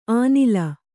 ♪ ānila